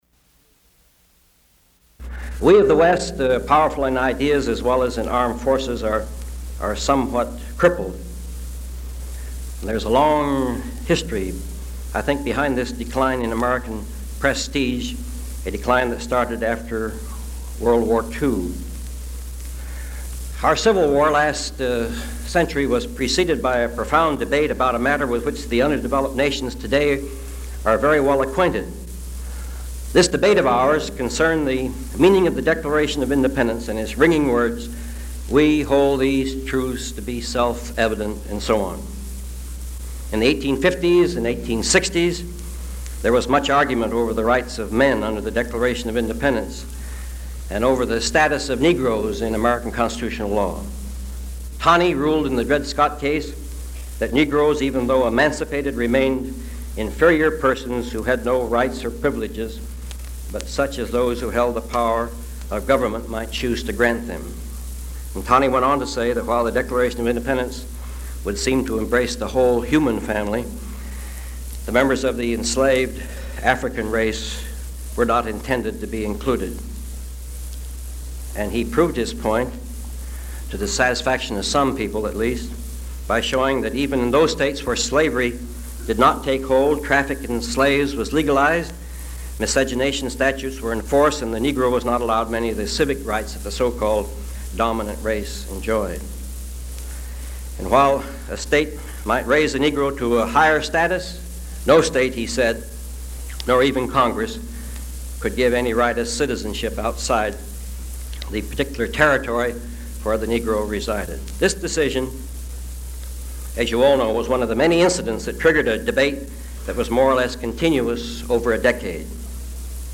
William O. Douglas talks at the Earl Warren Institute of Ethics on The sickness of America